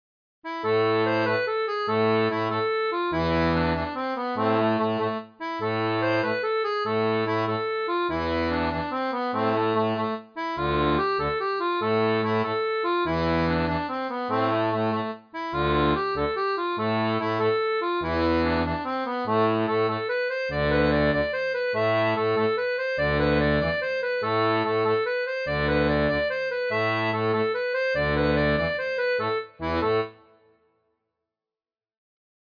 Air traditionnel portugais
• Une tablature pour diato à 2 rangs transposée en La
Extrait audio généré numériquement à partir de la tablature pour 2 rangs :
Folk et Traditionnel
Vira-Do-Minho-transposee-2-rangs.mp3